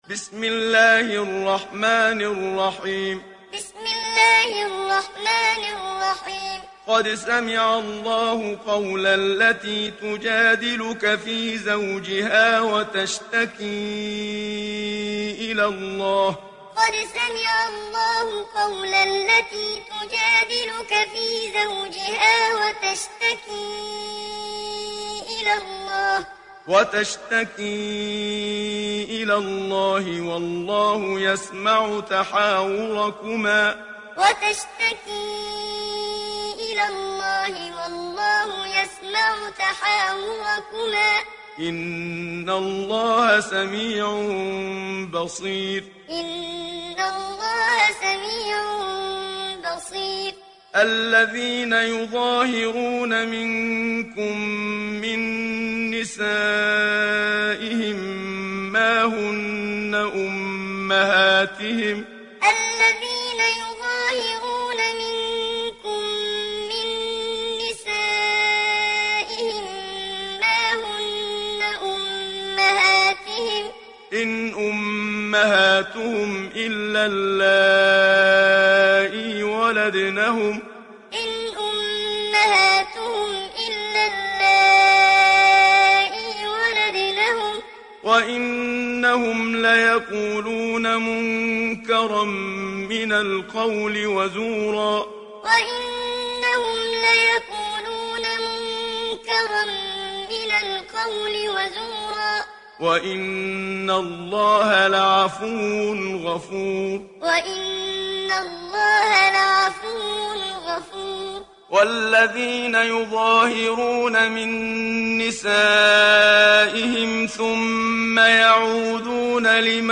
İndir Mücadele Suresi Muhammad Siddiq Minshawi Muallim
Muallim